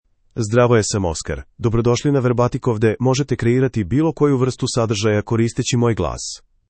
MaleSerbian (Serbia)
Oscar — Male Serbian AI voice
Voice sample
Listen to Oscar's male Serbian voice.
Oscar delivers clear pronunciation with authentic Serbia Serbian intonation, making your content sound professionally produced.